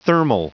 Prononciation du mot : thermal